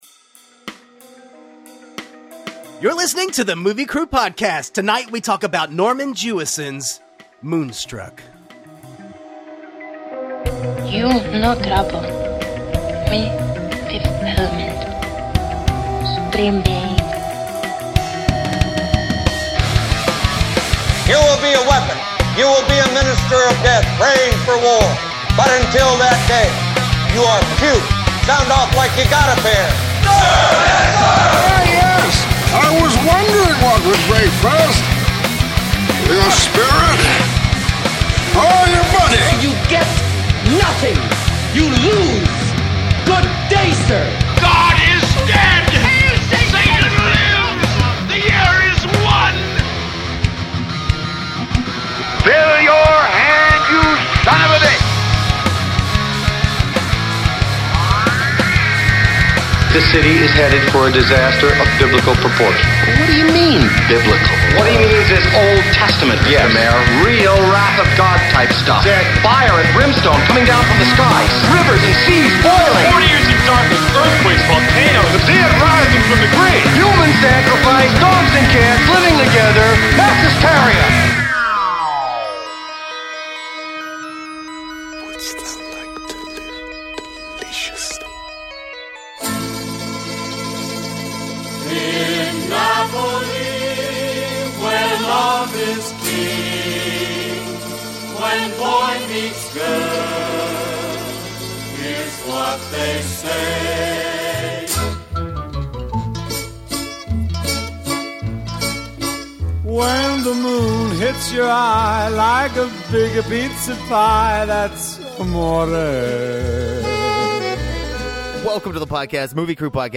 Filmmakers talking about movies because... Well, they would be doing it anyway!